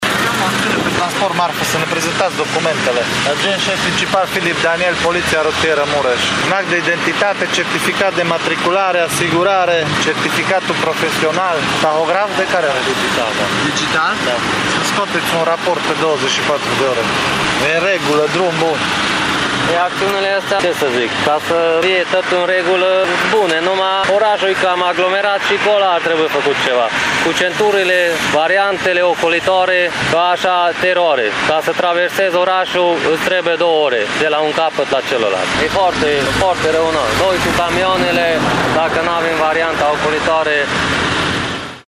Aceștia reproșează însă autorităților că nu au construit centuri și variante ocolitoare, motiv pentru care orașul se blochează pe toate rutele, mai ales în orele de vârf: